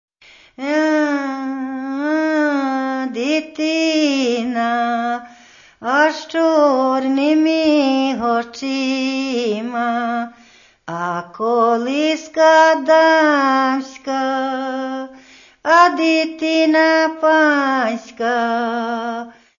Каталог -> Народная -> Аутентичное исполнение